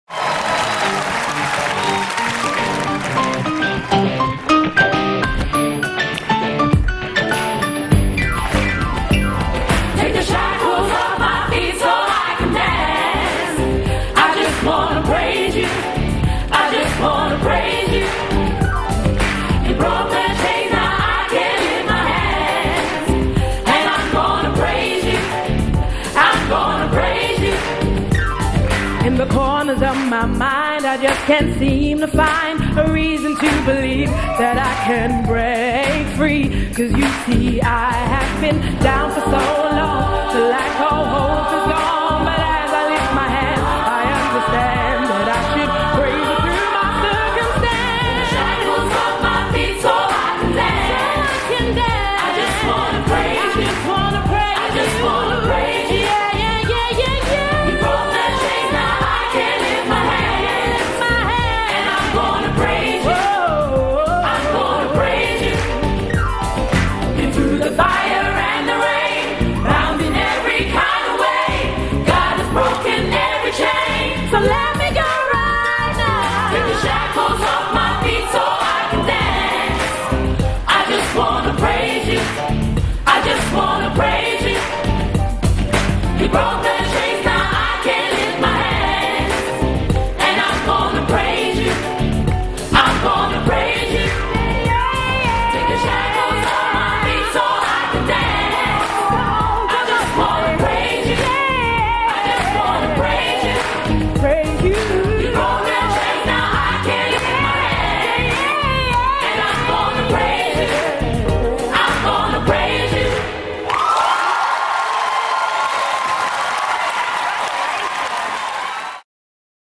Experimental